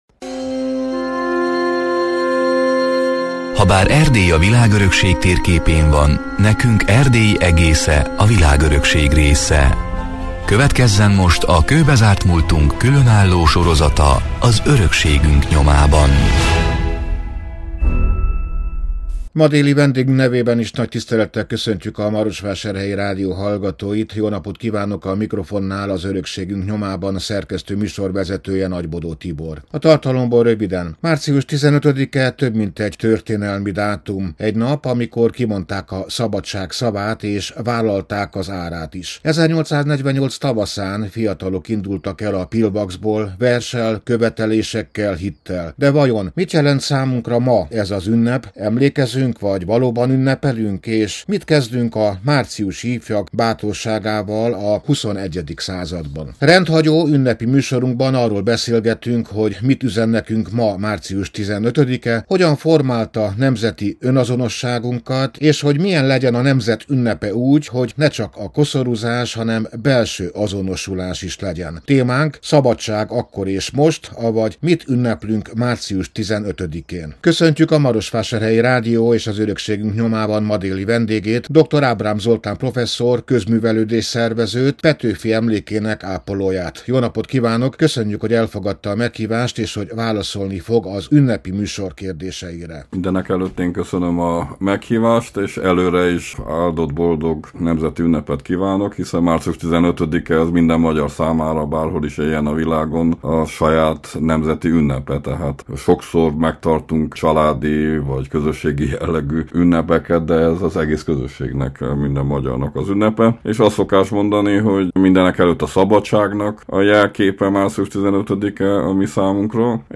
Mit kezdünk a márciusi ifjak bátorságával a 21. században? Rendhagyó, ünnepi műsorunkban arról beszélgetünk, hogy mit üzen nekünk ma március 15., hogyan formálta nemzeti önazonosságunkat, és milyen legyen a nemzet ünnepe úgy, hogy ne csak koszorúzás, hanem belső azonosulás is legyen.